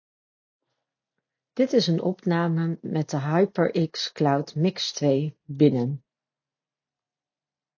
In de volgende opnames is goed te horen dat stemgeluid binnen beter tot zijn recht komt dan in de buitenopname. In beide opnames is het stemgeluid wel zeer goed te verstaan.
HyperX-cloud-mix2-binnen.mp3